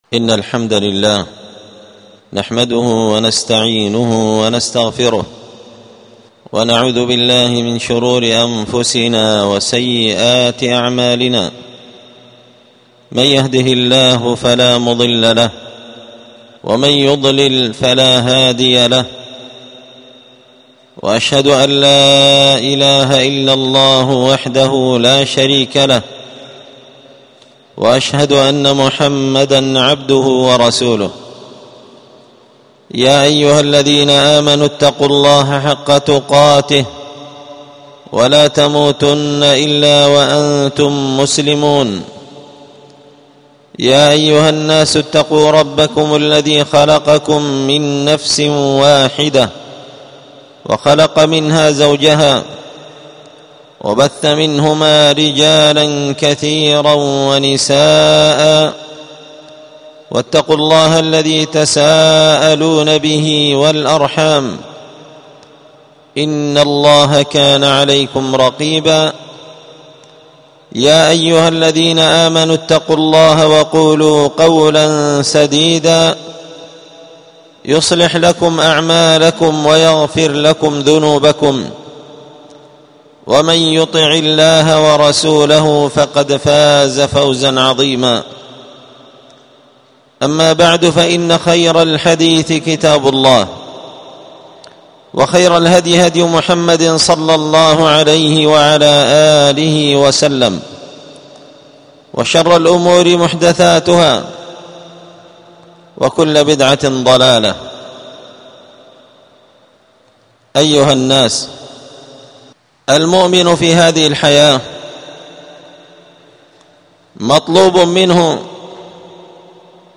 ألقيت هذه الخطبة بدار الحديث السلفية بمسجد الفرقان
الجمعة 21 جمادى الآخرة 1447 هــــ | الخطب والمحاضرات والكلمات | شارك بتعليقك | 75 المشاهدات